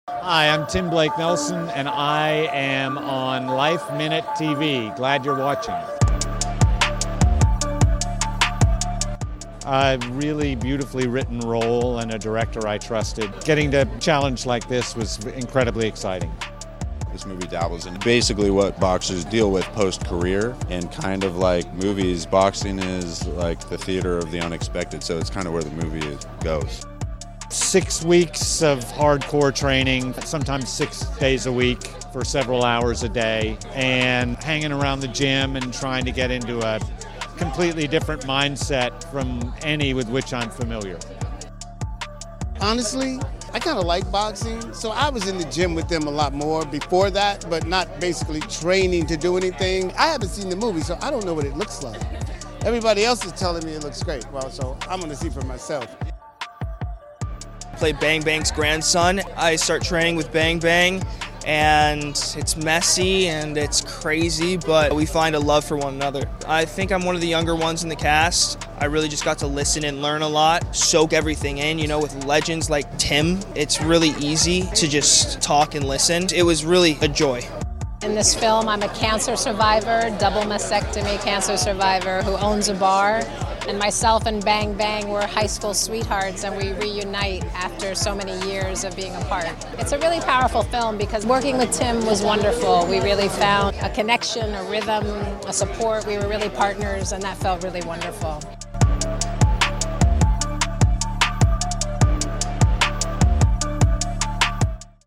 Tim Blake Nelson Talks Training for New Boxing Film Bang Bang at Tribeca Festival Premiere